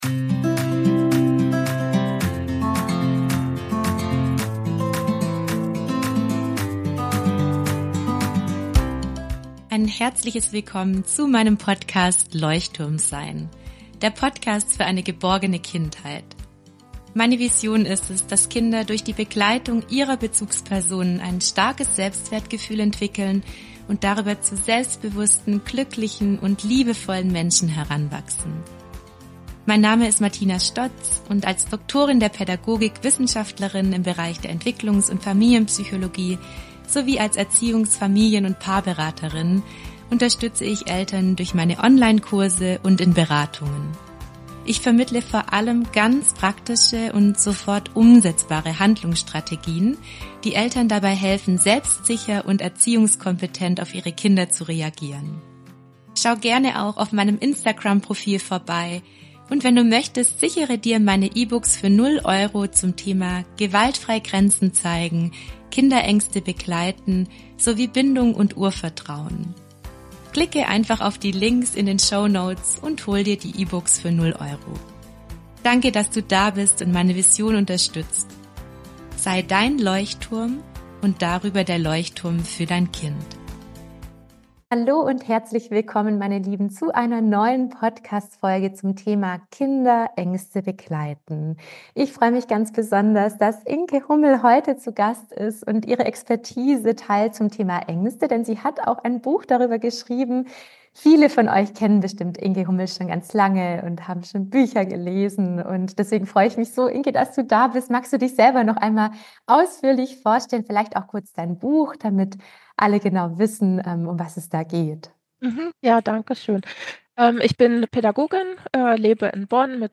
In meinem Podcast erwarten dich Interviews mit Expert*innen sowie Meditationen, die dir dabei helfen, wieder mehr Leichtigkeit, Vertrauen und Sicherheit im Familienalltag zu spüren.